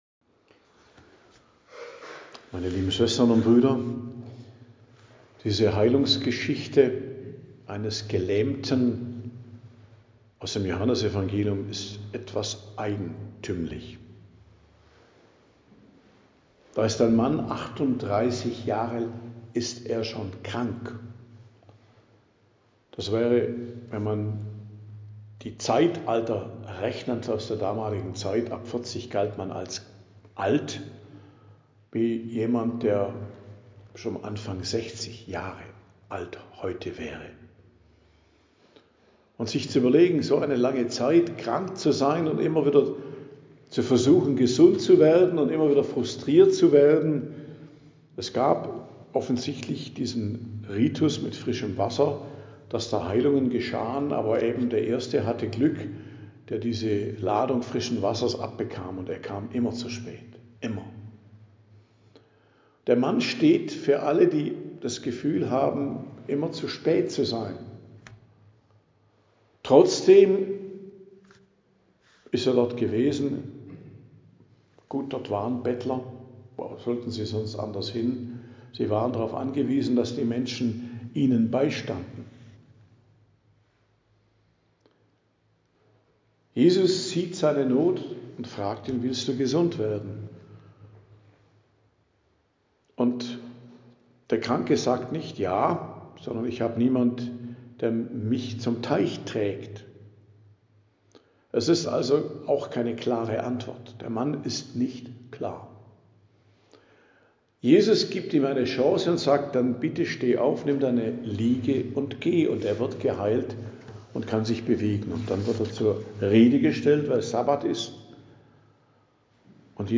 Predigt am Dienstag der 4. Woche der Fastenzeit, 17.03.2026